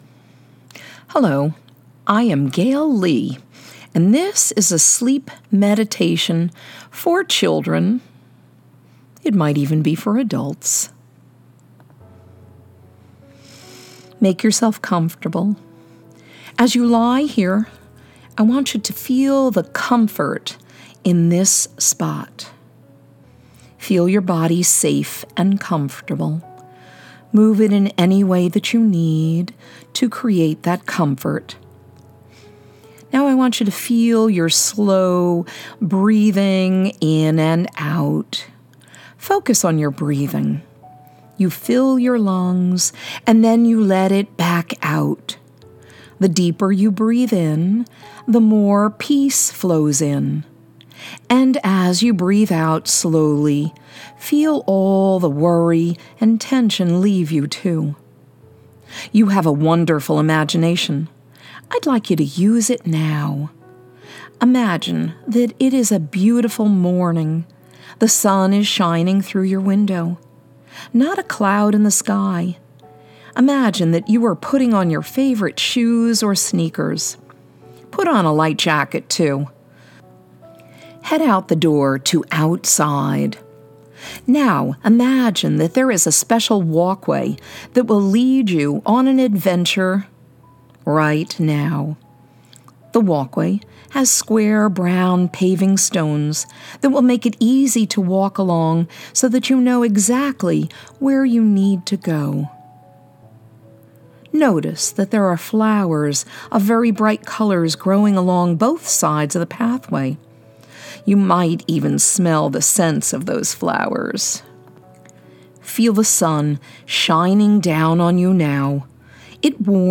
This guided meditation was created for children but can be used by adults.
Sleep-Meditation-for-Children.m4a